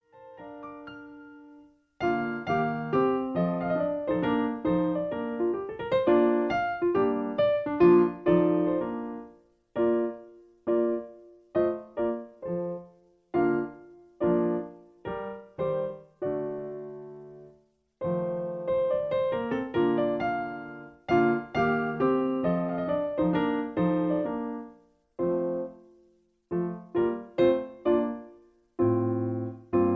Original key (B flat). Piano Accompaniment